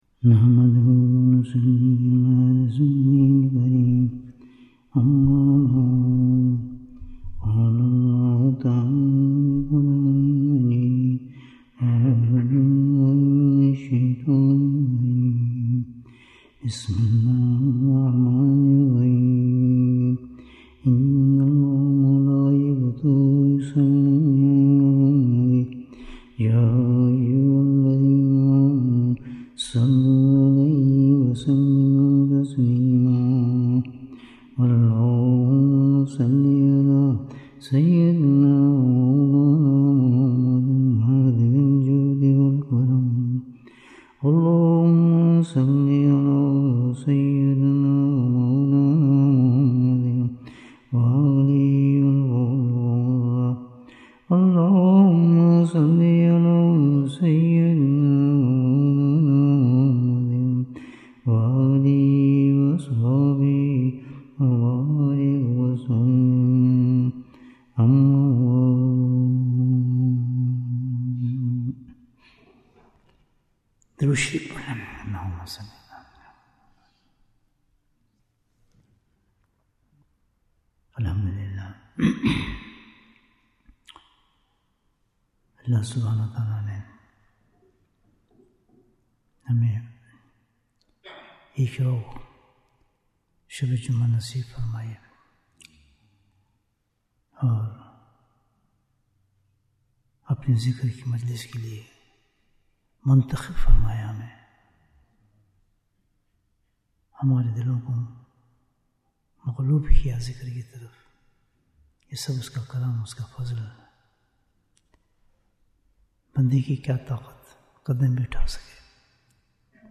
Bayan, 85 minutes9th October, 2025